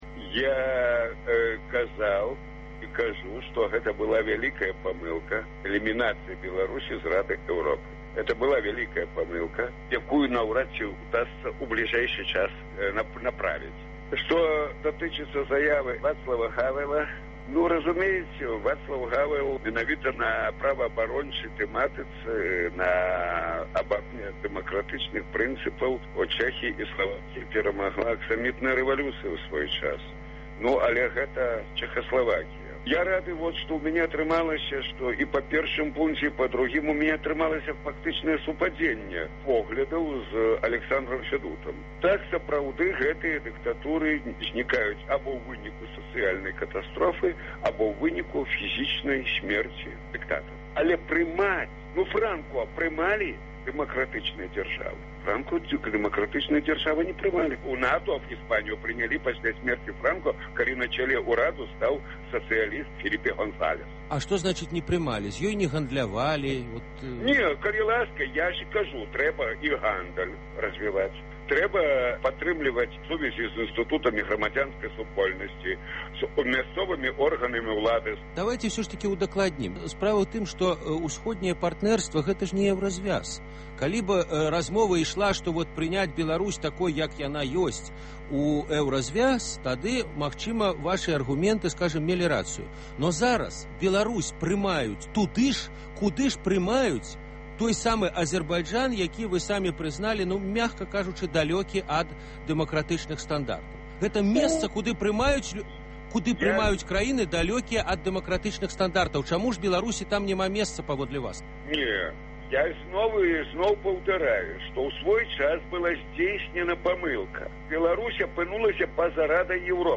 Агляд тэлефанаваньняў слухачоў